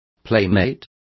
Complete with pronunciation of the translation of playmates.